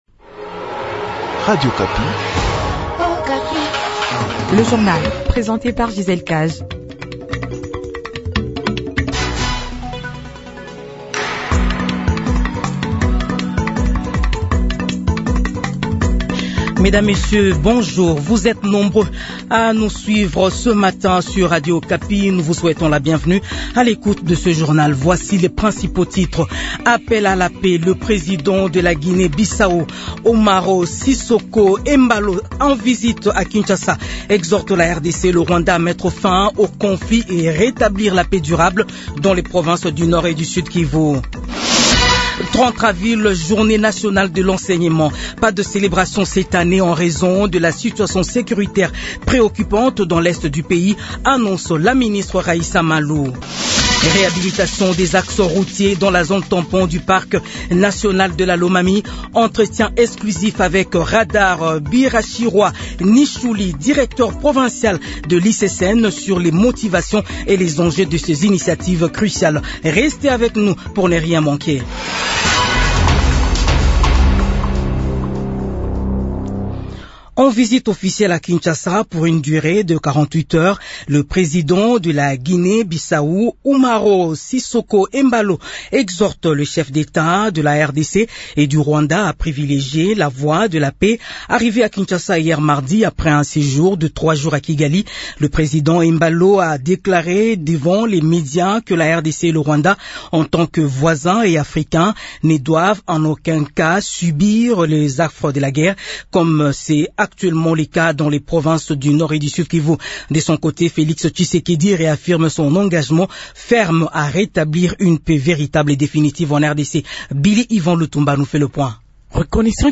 Journal 6h